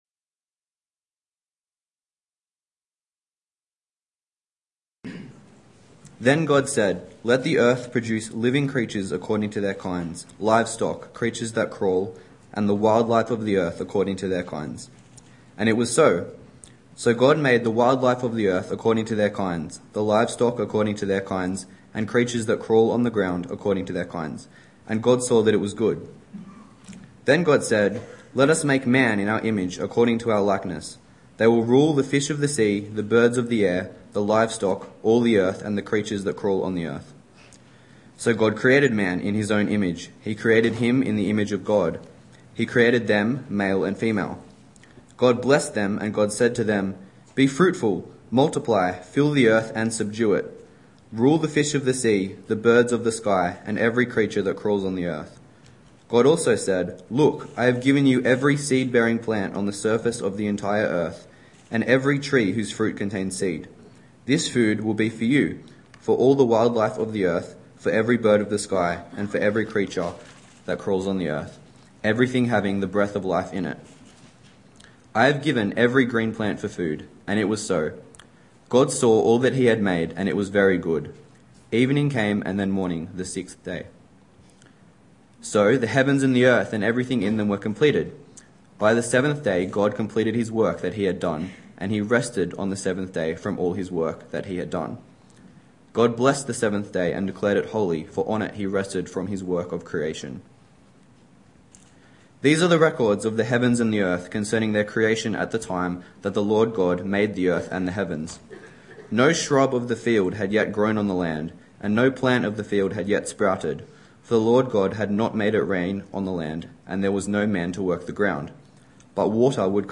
Evening Church